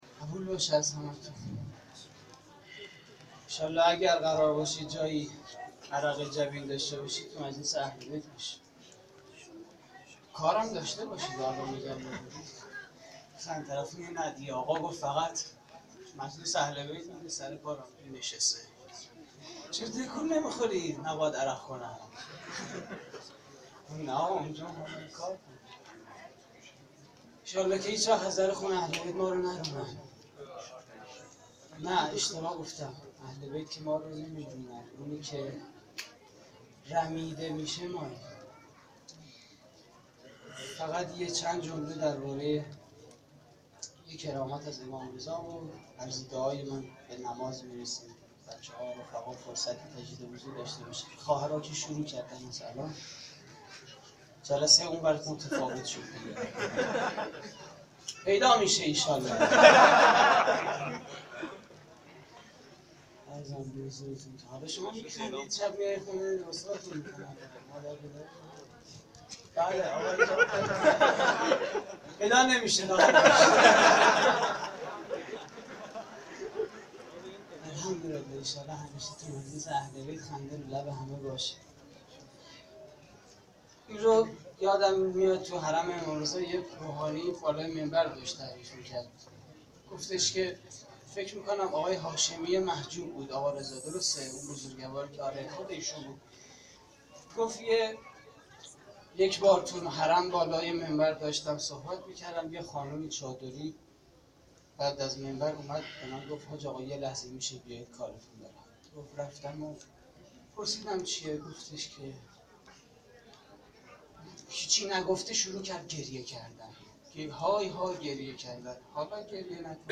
جشن ولادت امام رضا (ع) / هیئت زوارالزهرا (س) | نازی‌آباد - 23 مرداد 95
سخنرانی: کرامات امام رضا (ع)؛ پخش آنلاین |